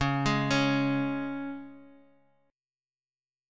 Db5 chord
Db-5th-Db-x,5,7,7,x,x-8-down-Guitar-Half_Step Down.m4a